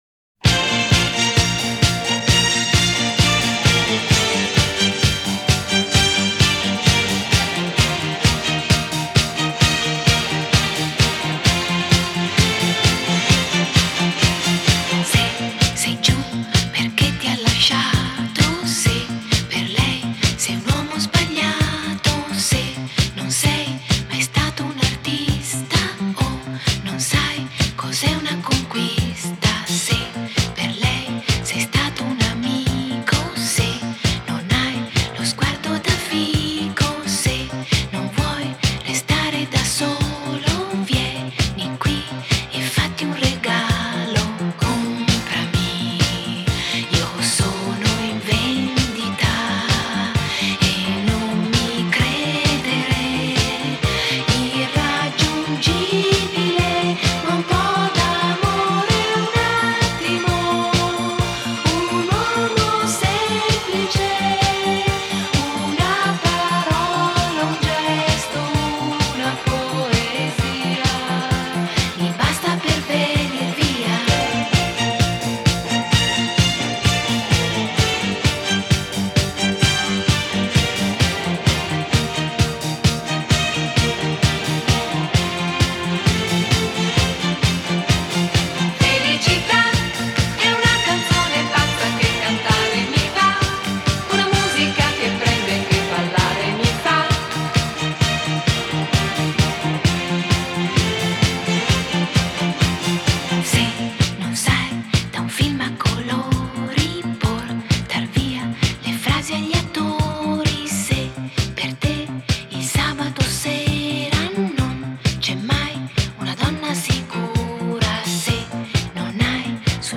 Genre: Pop, Disco,Nu-Disco,Dance